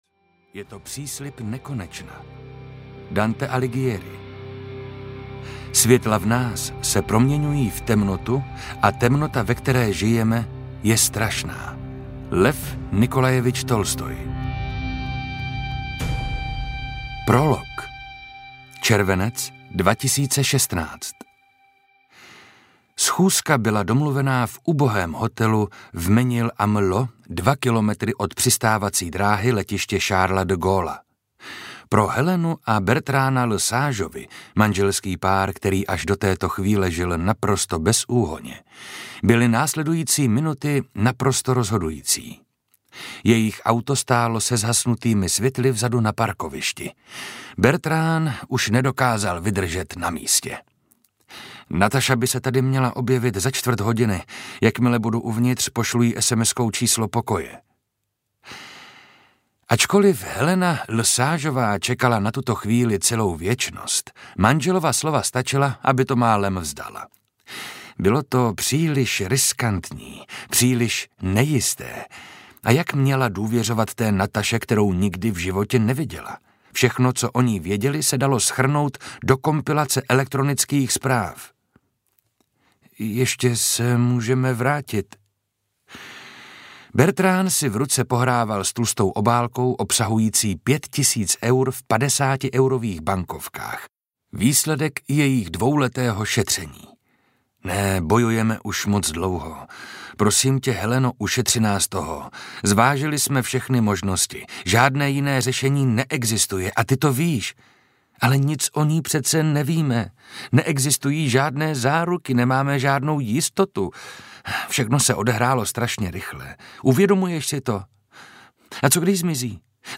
Luca audiokniha
Ukázka z knihy
• InterpretMarek Holý